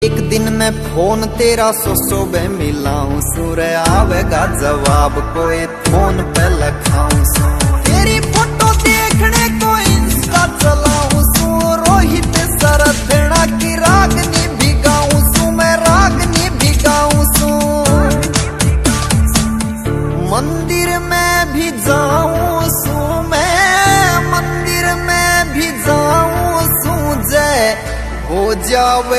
Haryanvi Song